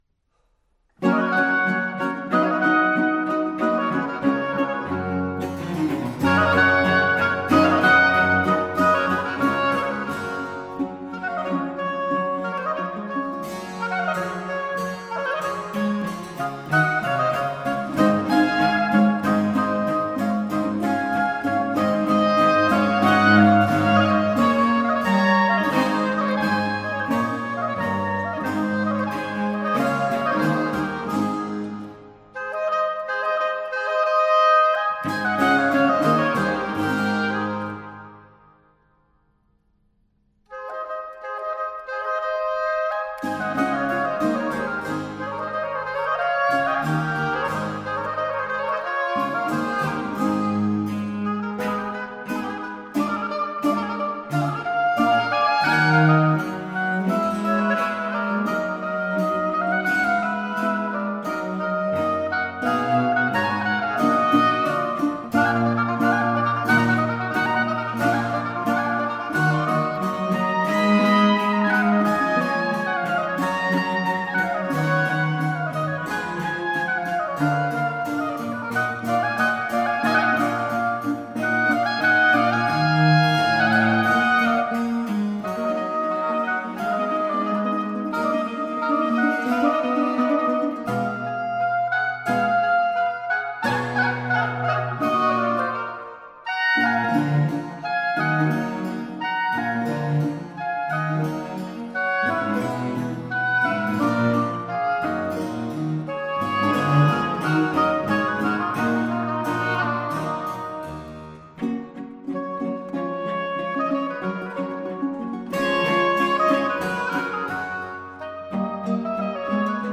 07_Pla__Sonata-for-two-oboes-and-bcinGmajor-1st_Movt.mp3